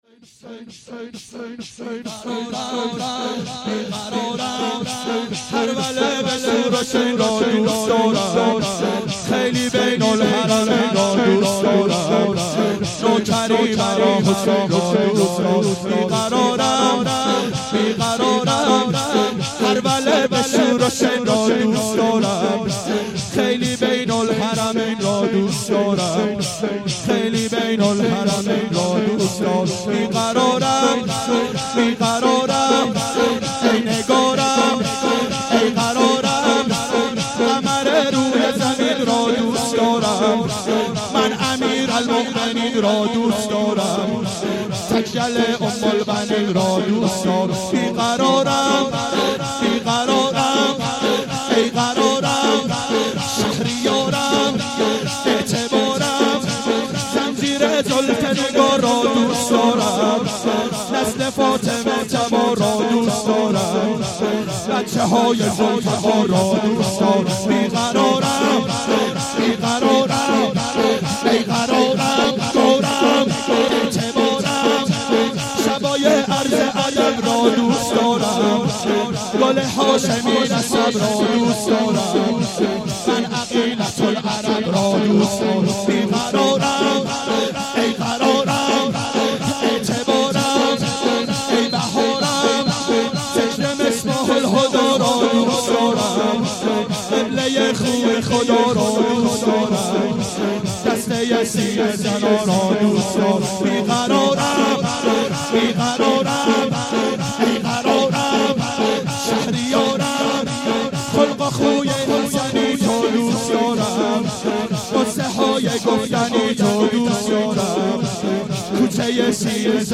دهه اول صفر سال 1391 هیئت شیفتگان حضرت رقیه سلام الله علیها (شام غریبان)